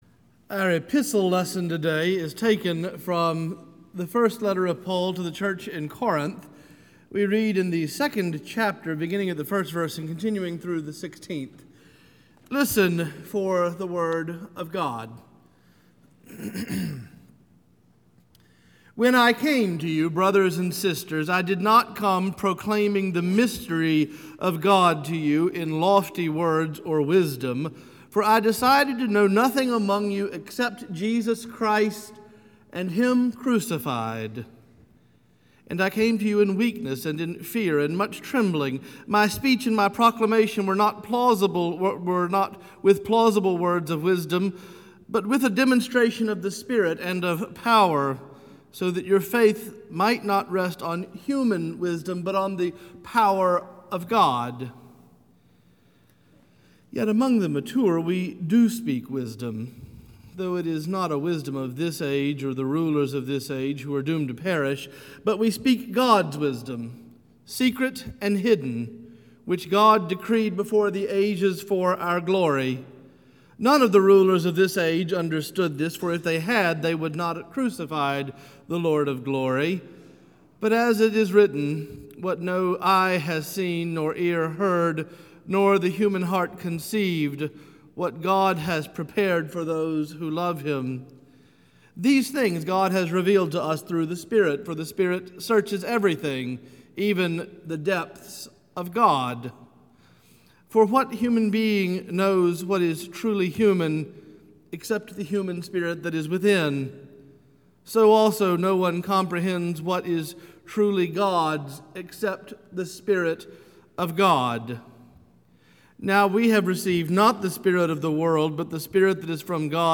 Morningside Presbyterian Church - Atlanta, GA: Sermons: The Mystery of God